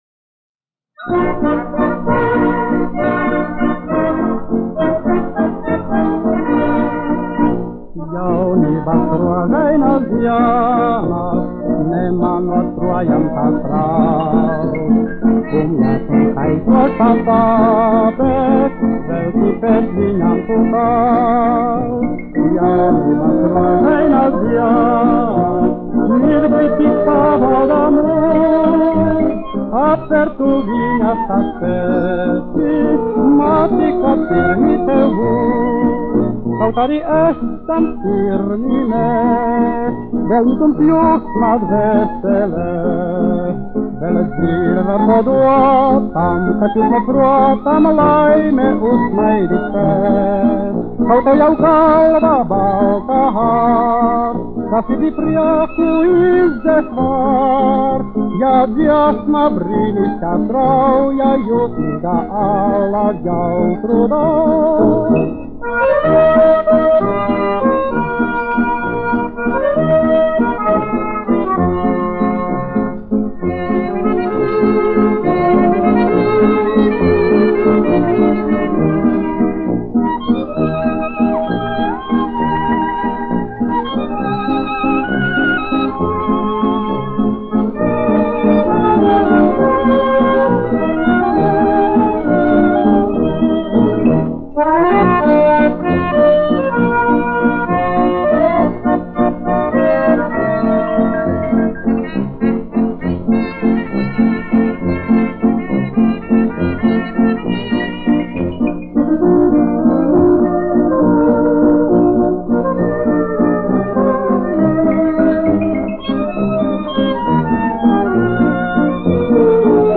dziedātājs
1 skpl. : analogs, 78 apgr/min, mono ; 25 cm
Populārā mūzika
Skaņuplate